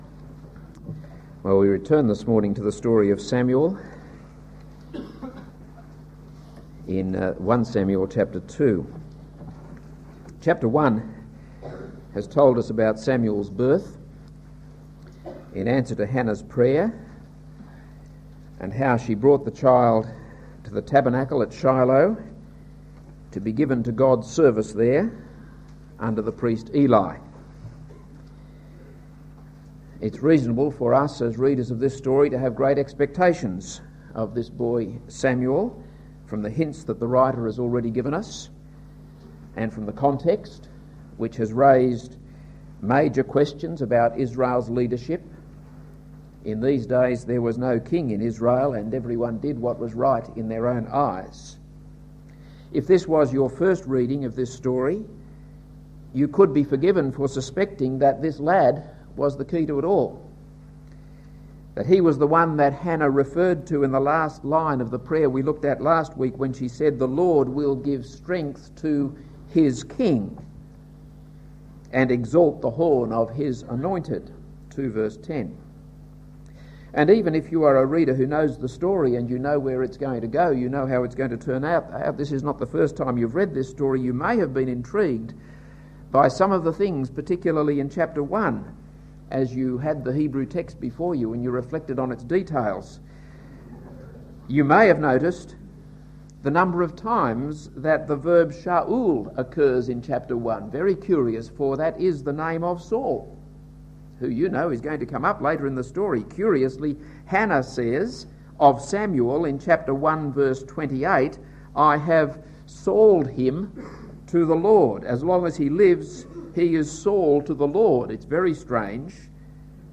This is a sermon on 1 Samuel 2:11.